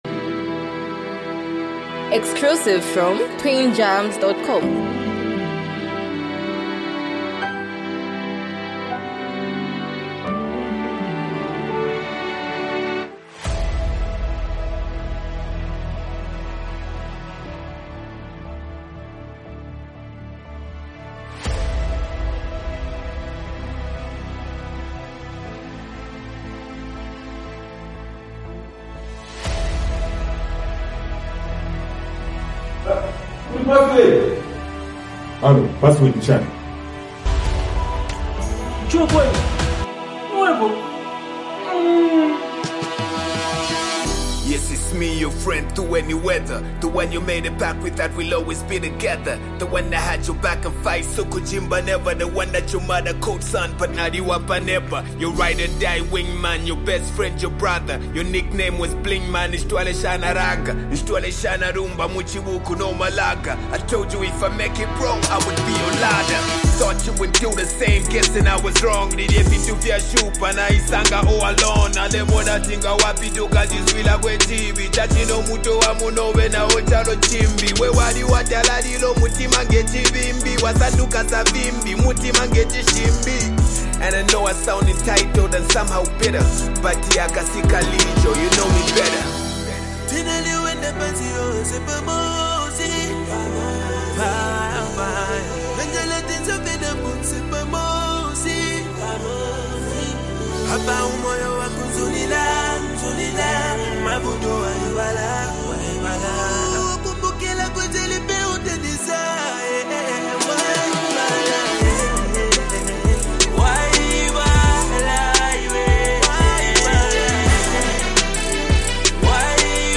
powerful and emotional song
Backed by a soulful instrumental